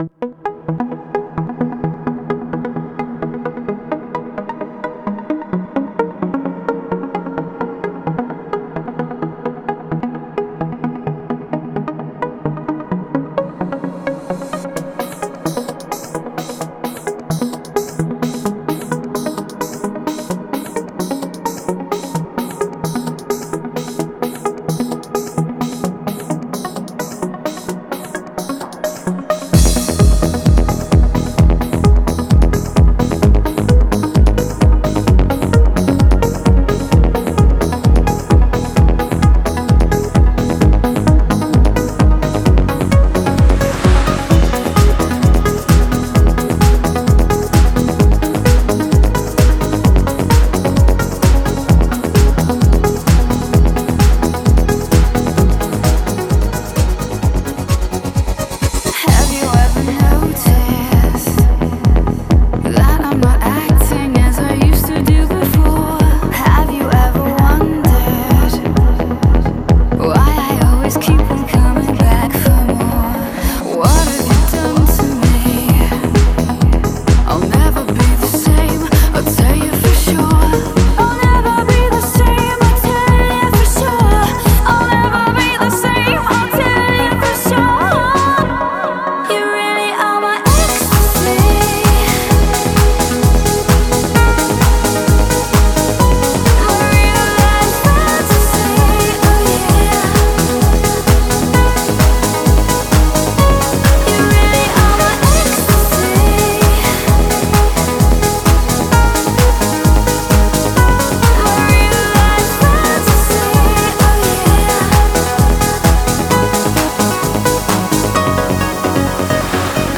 trance музыка